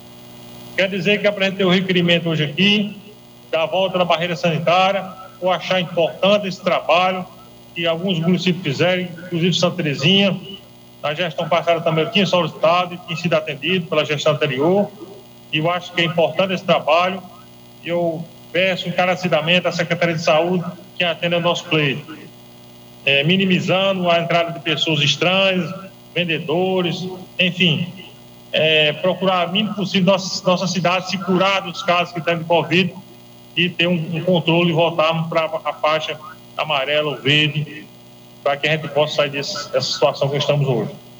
O documento foi apresentando na sessão ordinária remota ocorrida nessa sexta-feira (26) e transmitida pela Rádio Conexão FM.